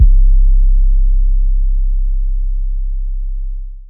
YM 808 11.wav